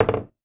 Ball Sink.wav